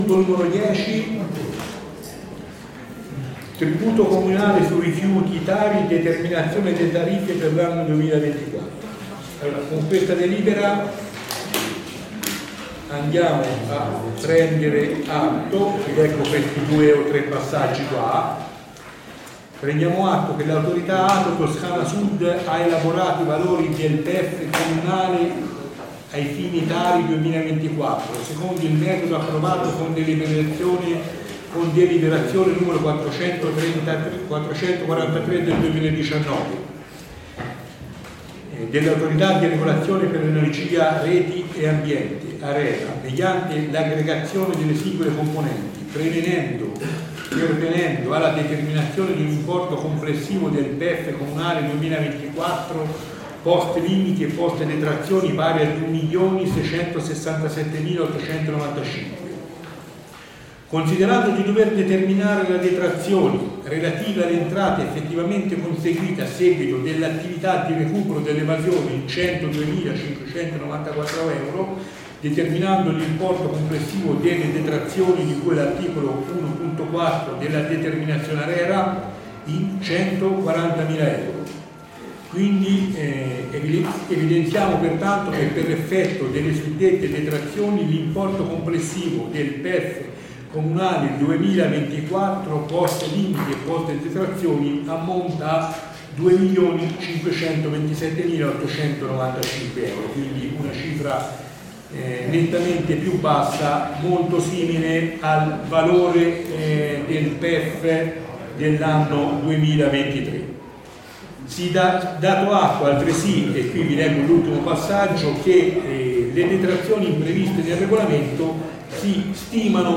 Registrazioni Consiglio - Audio 2024
Audio Consiglio del 27 Giugno 2024